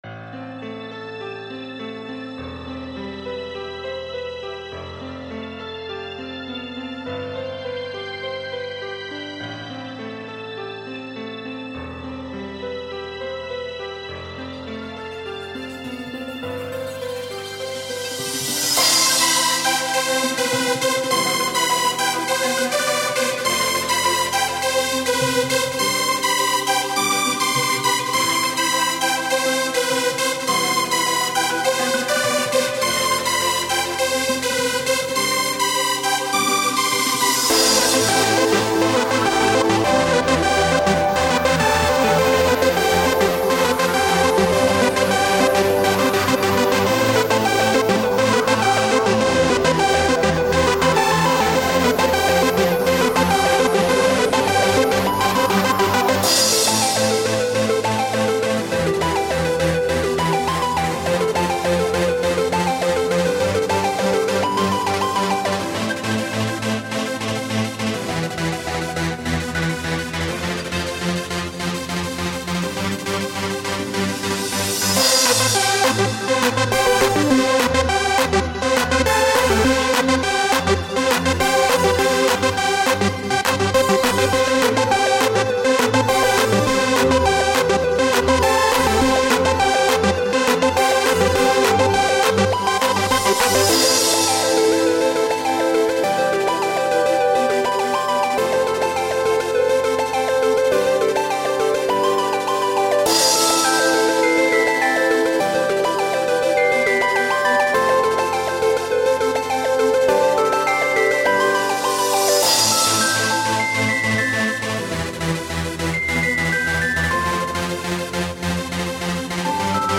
dance/electronic
My second song made on FruityLoops.
this is quite a good track, bit to cheesy for me needs beat
Techno
Electro
New age